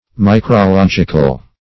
Meaning of micrological. micrological synonyms, pronunciation, spelling and more from Free Dictionary.